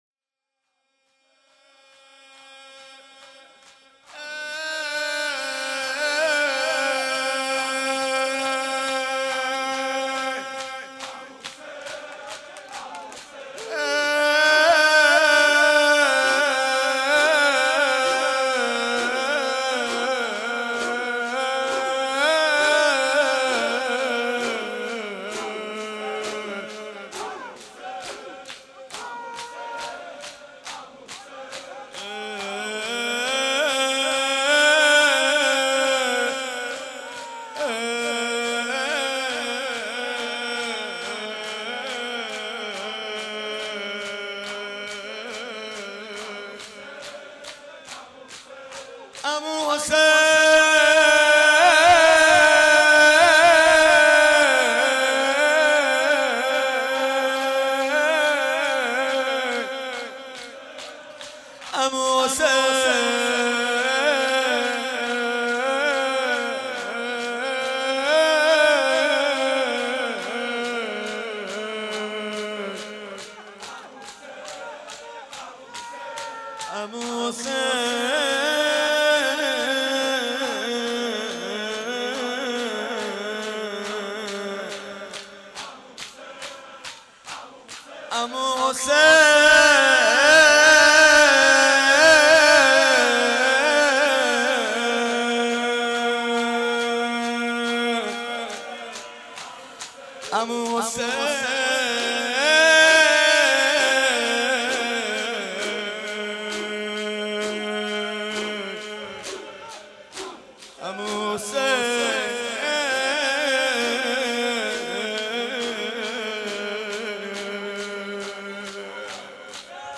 مناسبت : شب پنجم محرم
قالب : شور